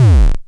KLONE_BaSs A145Short.wav